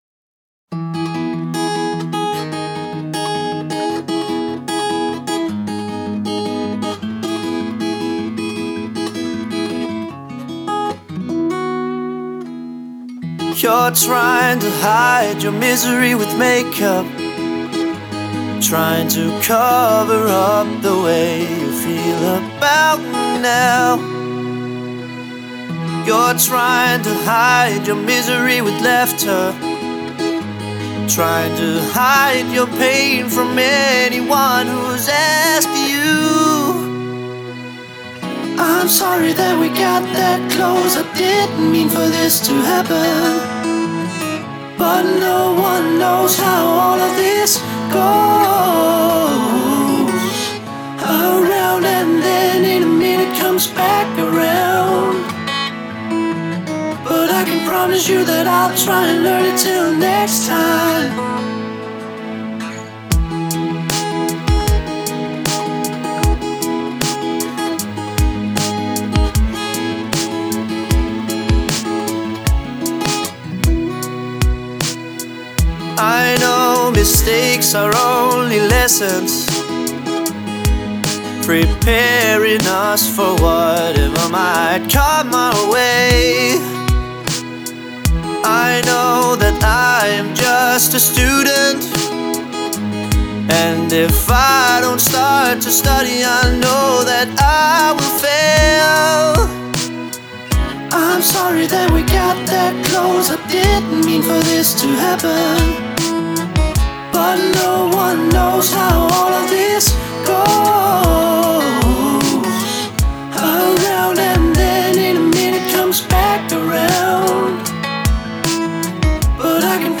Help recording acoustic guitar
The acoustic guitar sound is not turning out how I want it to. He has an Rode NT2A and M3.